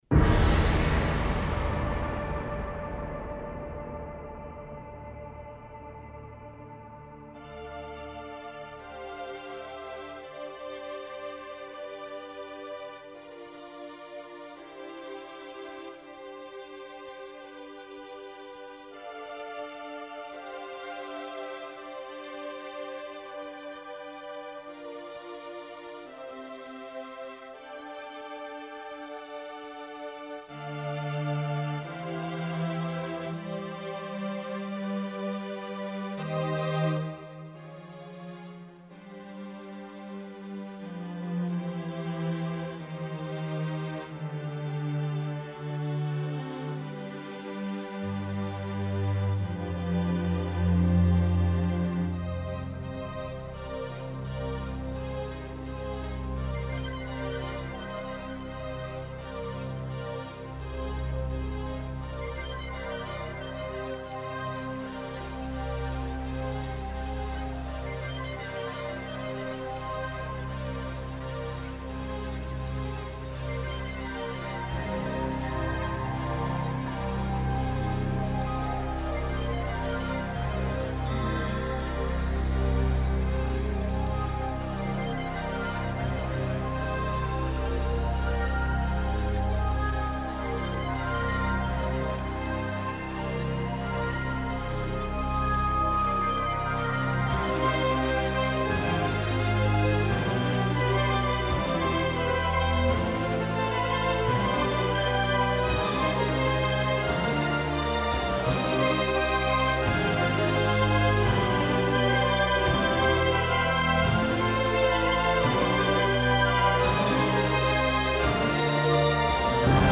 Entitled "Fabature", it was played as the lights dimmed and the curtain rose... metaphorically speaking, as there actually wasn't one... and was designed to get the audience's juices flowing with the promise of excitement ahead.